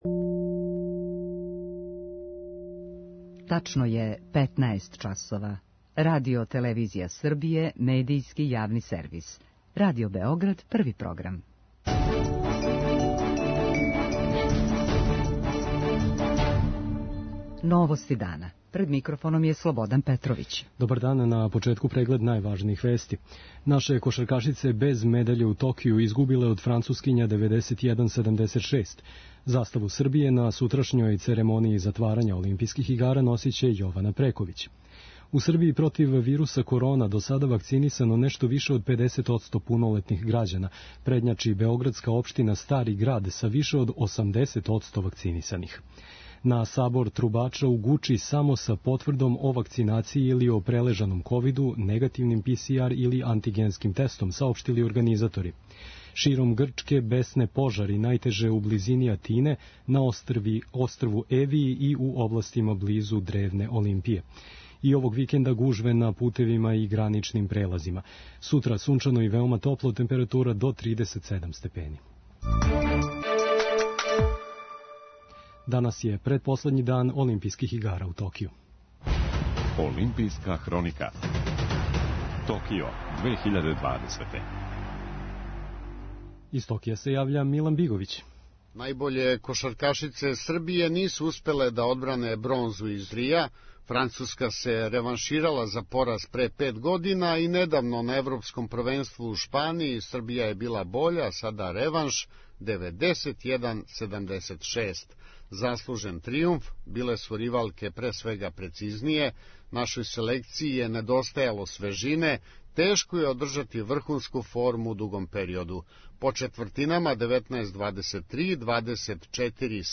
Ватерполисти ће бранити златну медаљу из Рија, у финалу са Грчком. преузми : 6.29 MB Новости дана Autor: Радио Београд 1 “Новости дана”, централна информативна емисија Првог програма Радио Београда емитује се од јесени 1958. године.